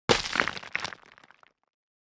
UI_StoneFract_02.ogg